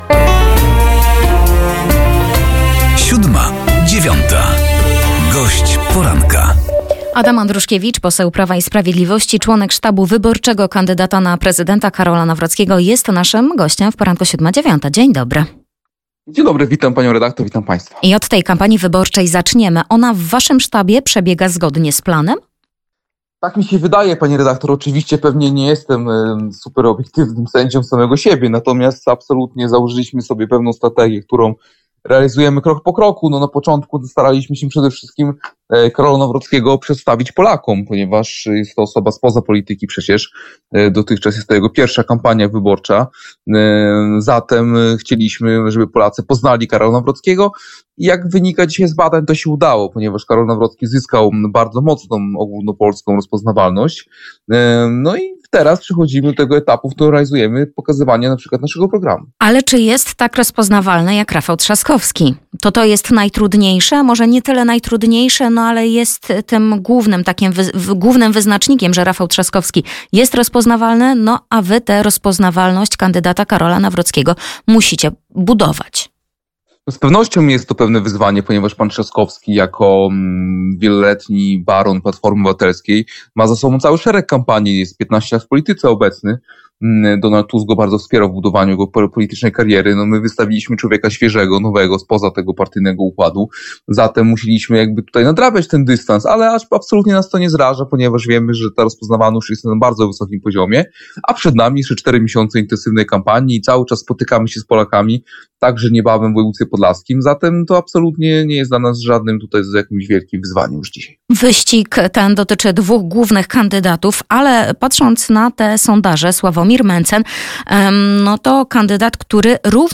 Gościem Poranka Siódma9 był poseł PiS Adam Andruszkiewicz